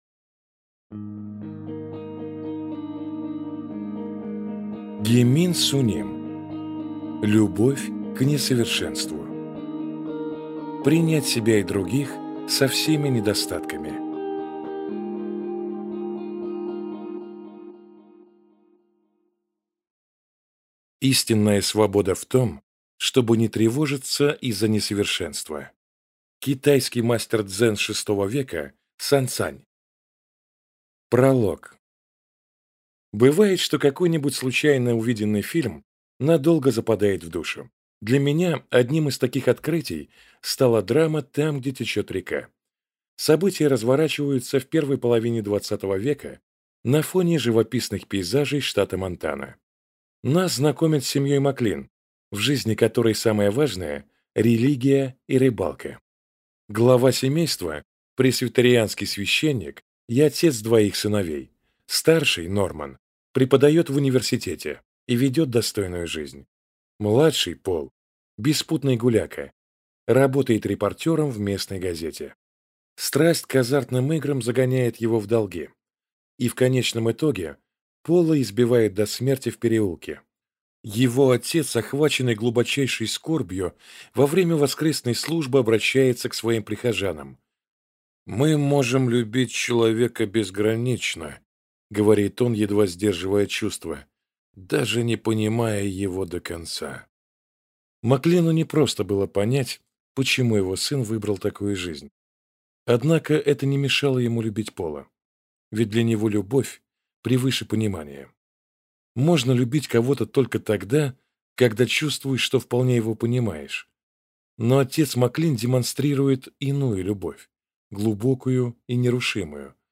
Аудиокнига Любовь к несовершенству. Принять себя и других со всеми недостатками | Библиотека аудиокниг